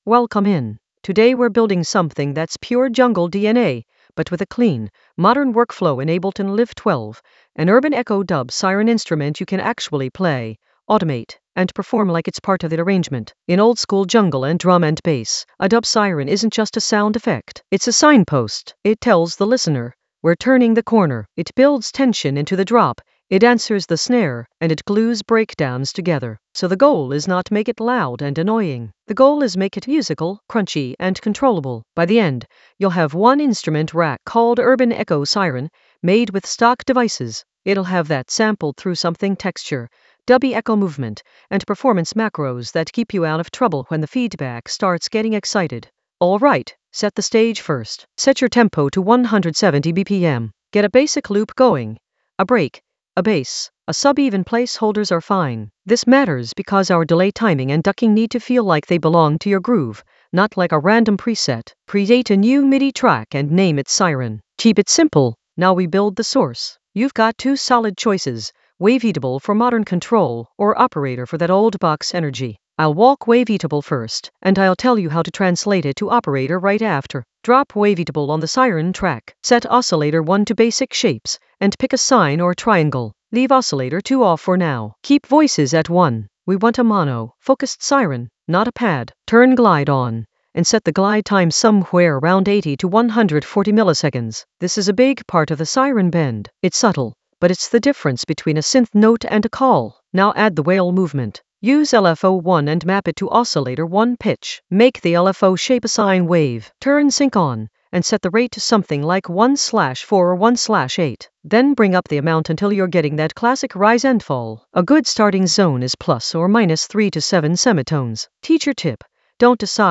Narrated lesson audio
The voice track includes the tutorial plus extra teacher commentary.
An AI-generated intermediate Ableton lesson focused on Urban Echo Ableton Live 12 dub siren playbook with crunchy sampler texture for jungle oldskool DnB vibes in the FX area of drum and bass production.